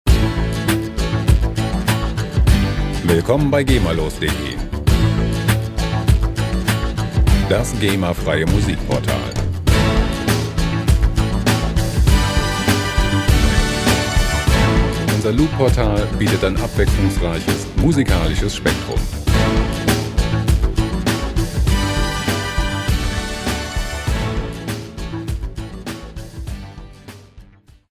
gemafreie Musik Loops in der Rubrik "Karaoke"
Musikstil: Rock
Tempo: 100 bpm